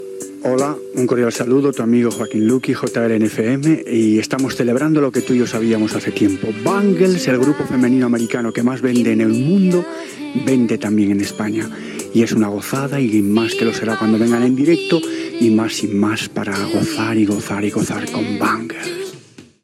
Presentació d'un tema musical
Musical
FM